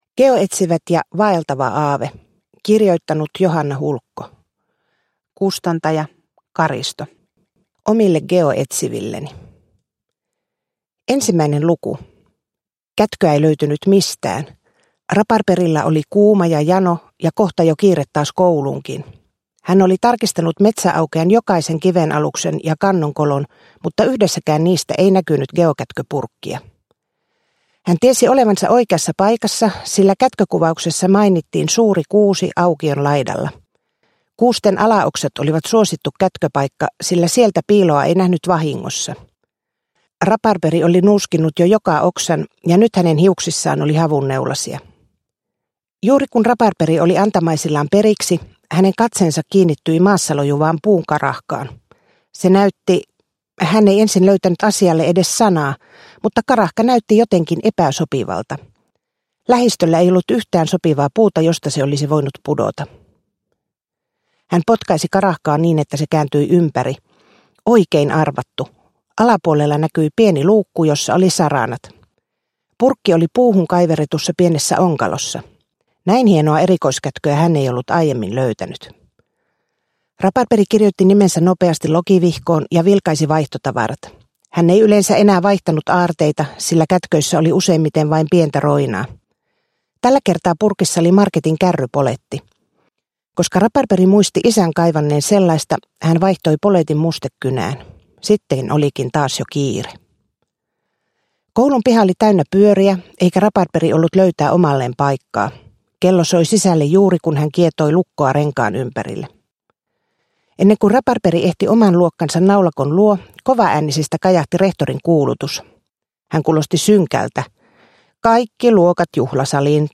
Geoetsiva¨t ja vaeltava aave – Ljudbok – Laddas ner